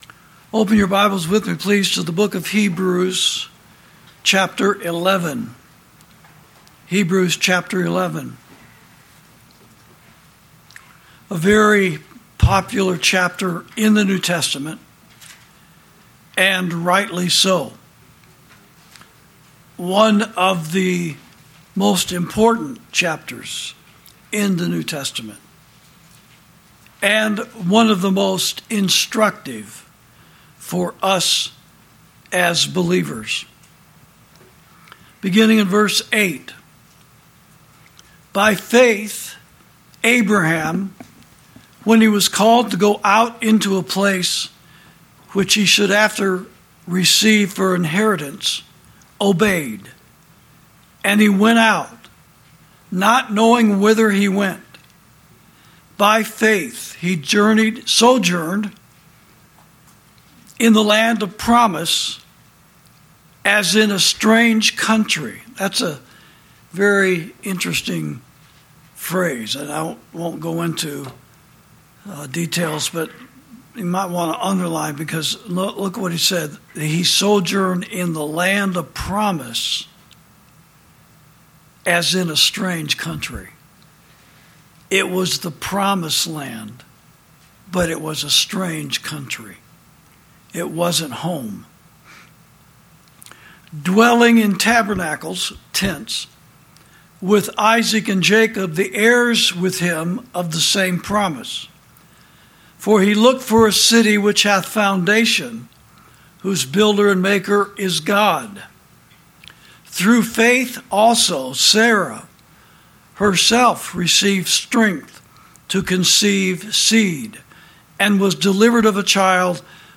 Sermons > The Pastor Who Birthed America